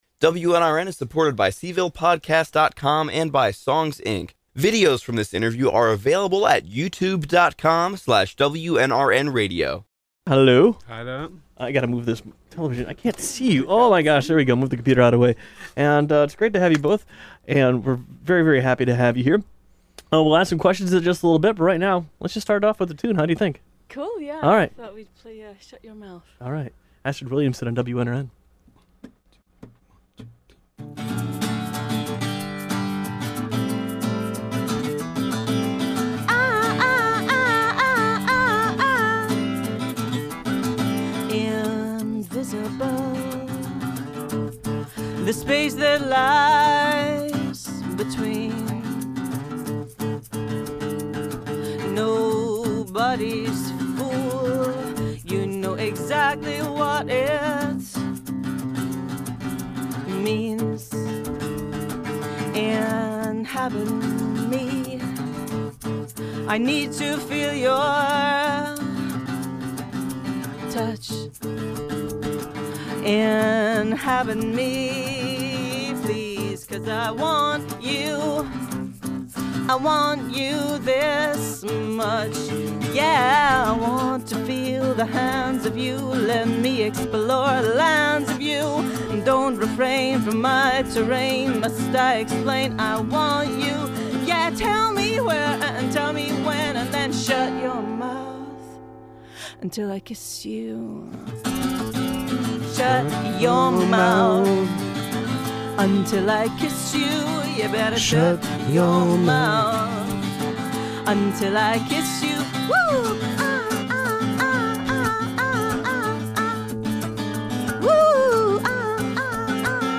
Scottish musician
in this fantastic in-studio.